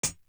Closed Hats
Ninety One Hat.wav